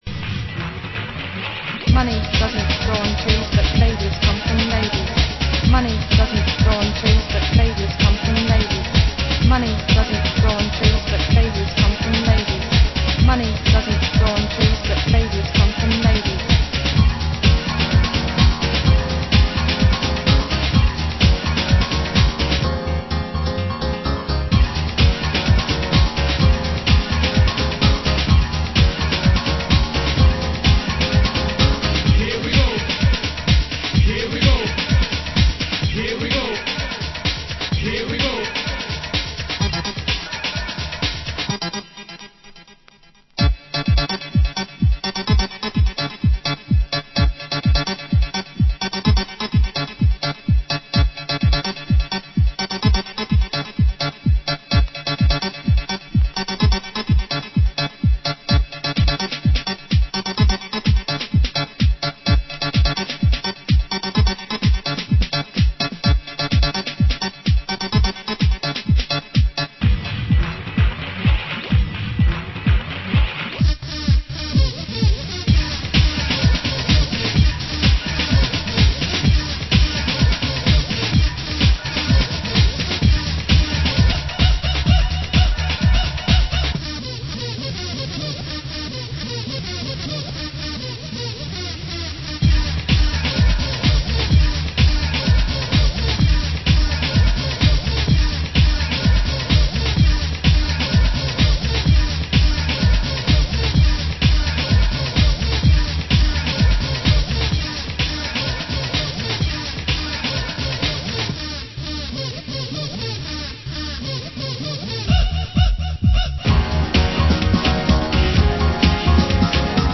Format: Vinyl 12 Inch
Genre: Hardcore